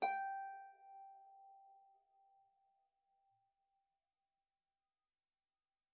KSHarp_G5_mf.wav